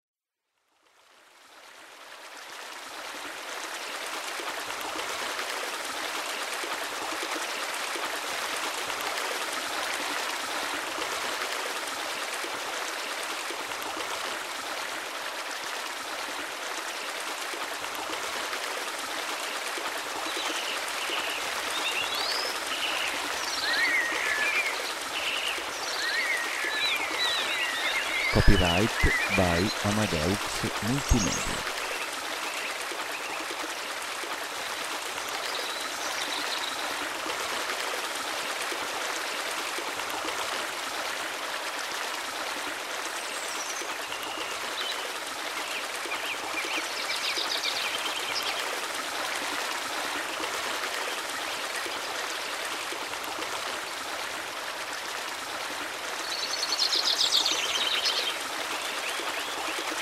6.AT002-ruscello-cinguettio-hrm-demo
AT002-ruscello-cinguettio-hrm-demo.mp3